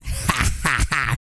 Index of /90_sSampleCDs/ILIO - Vocal Planet VOL-3 - Jazz & FX/Partition I/2 LAUGHS
E-GRUNT 309.wav